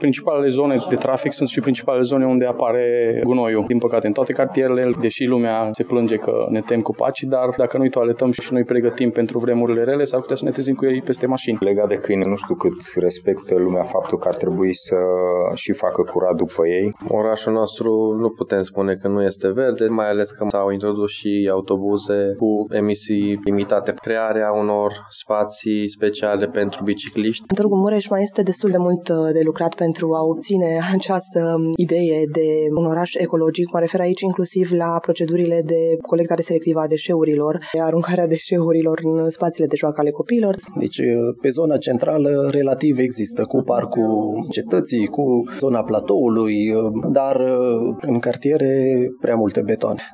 Târgumureșenii remarcă o îmbunătățire a situației în ultimii ani, însă lipsesc spațiile verzi în unele cartiere, dar și pistele de biciclete: